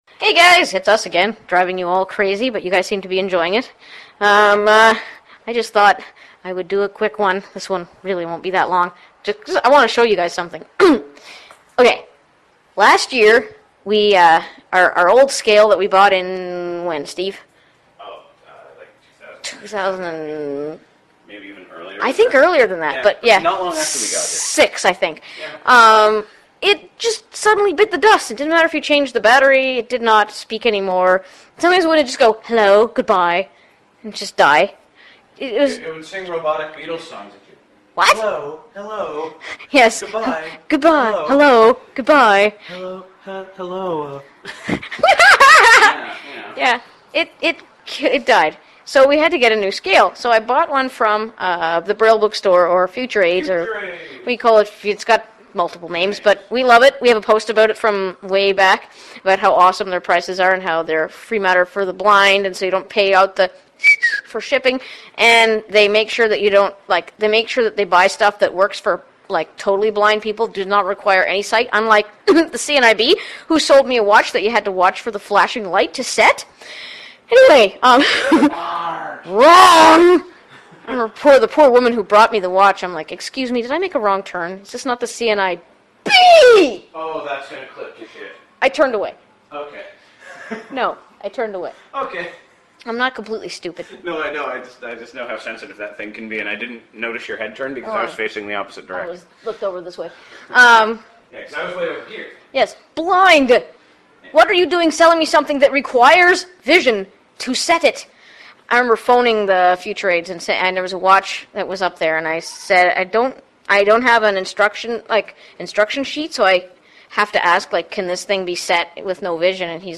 I got to show you our talking scale…which sounds like my mom! It’s kinda creepy hearing a voice that sounds like your mom telling you your weight.